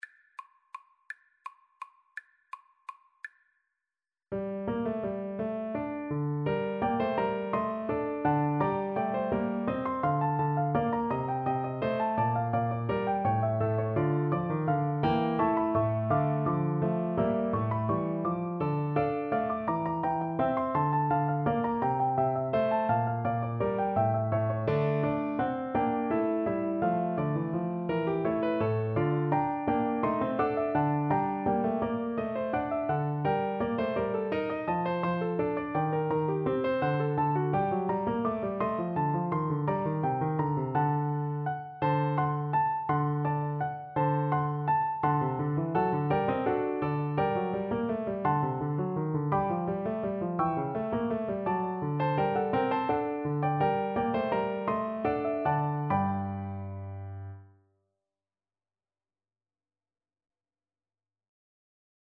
3/8 (View more 3/8 Music)
Classical (View more Classical French Horn Music)